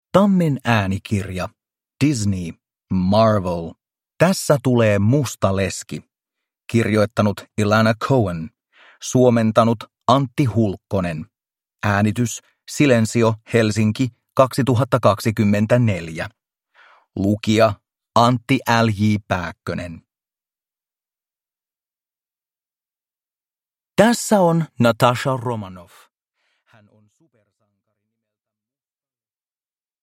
Tässä tulee Musta Leski. Luen itse – Ljudbok